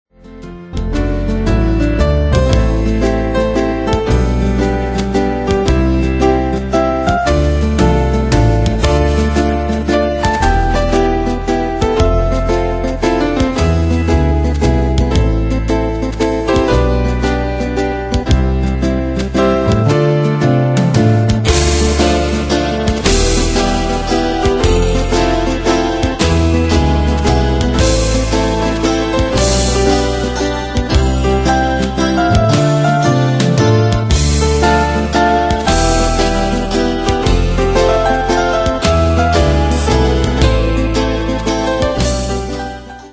Danse/Dance: Ballade Cat Id.
Pro Backing Tracks